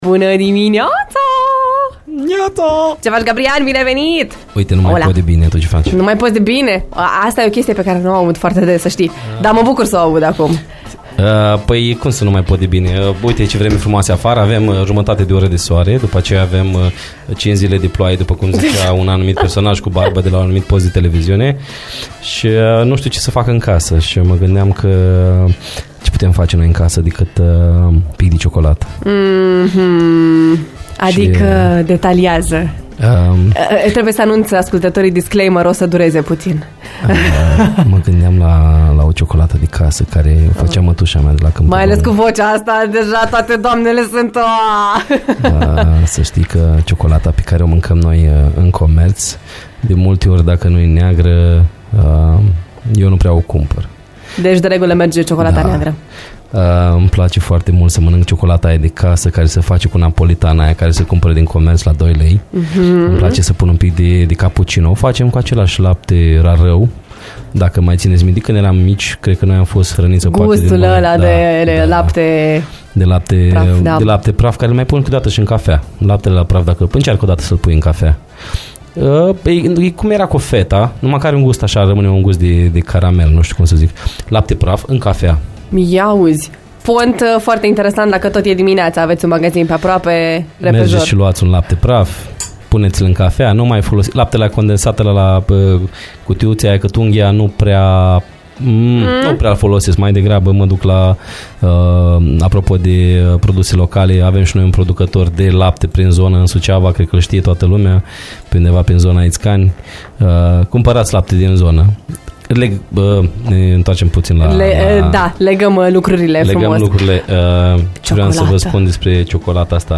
Stăm foarte bine la nivel de desert, iar subiectul ciocolatei este cum nu se poate mai potrivit. Arome, gusturi, concentrație de cacao și sfaturi avizate, în rubrica noastră deja consacrată. Iată cum a decurs discuția: